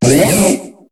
Cri de Limaspeed dans Pokémon HOME.